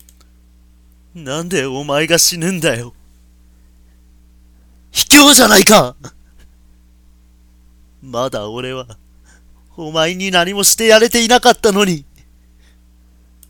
お昼の台本覚えの合間に、少し息抜きがてら録音してみました。
勢いが足らないですか。